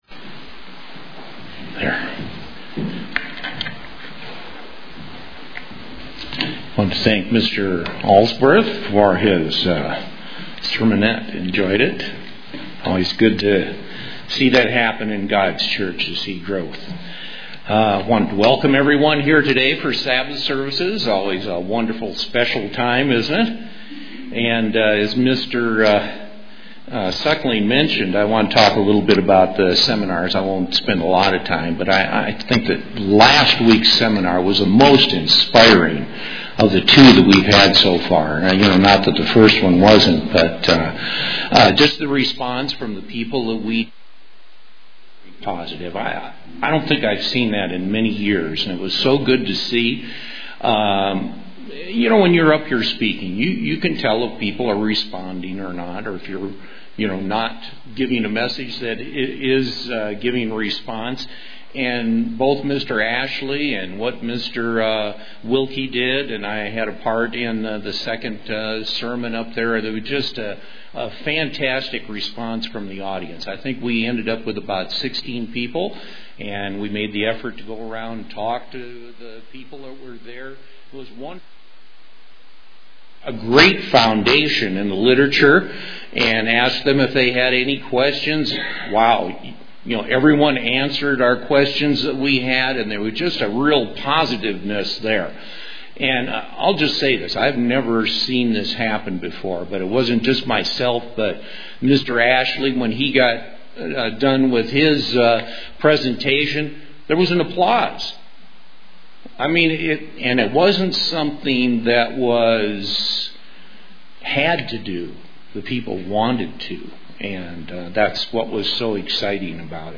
UCG Sermon Studying the bible?
Given in Colorado Springs, CO